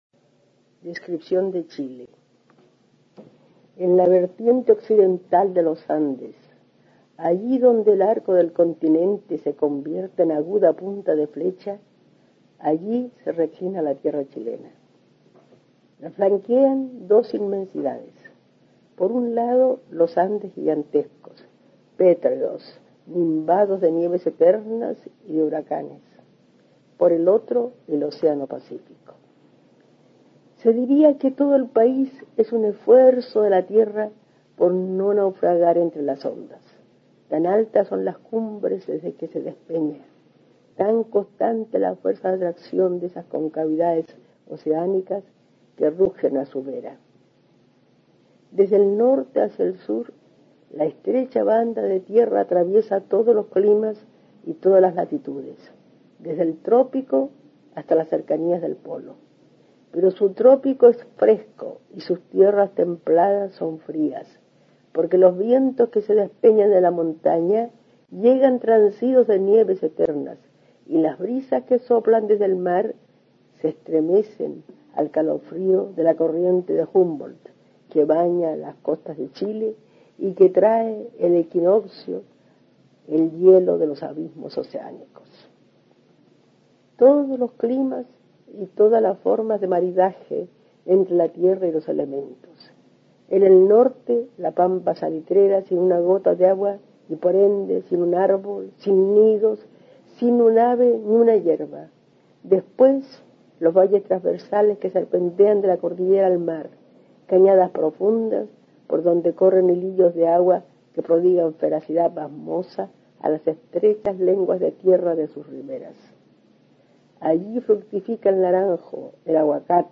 Aquí se puede escuchar a Amanda Labarca (1886-1975) leyendo su ensayo Descripción de Chile.